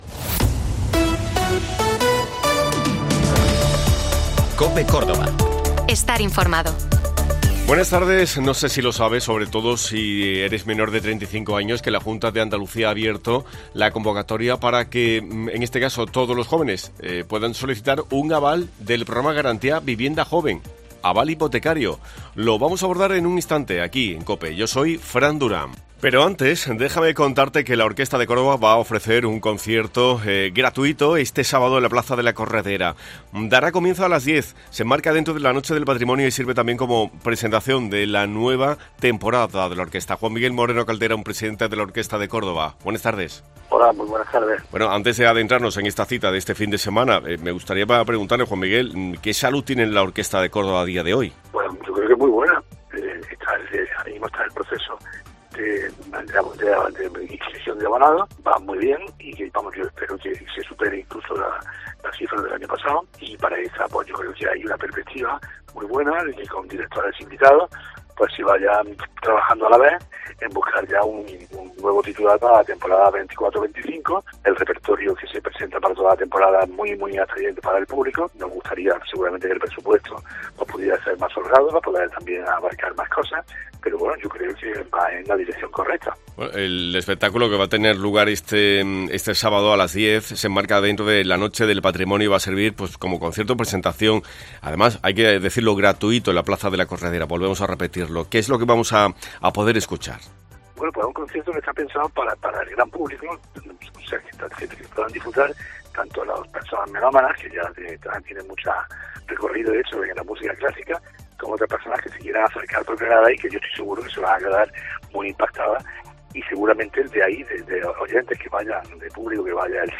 La Junta abrirá en septiembre la convocatoria para solicitar el aval hipotecario para jóvenesLa medida se aplicará para viviendas con un precio de referencia máximo de 295.240 euros con carácter general. Hoy hemos ampliado esta información con la delegada de Fomento, Carmen Granados.